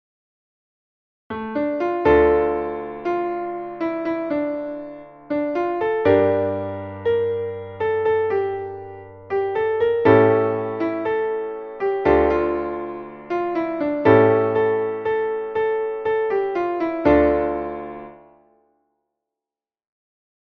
Israelisches Volkslied für den Frieden